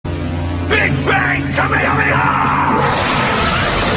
Big Bang Kamehameha Sound Effect Free Download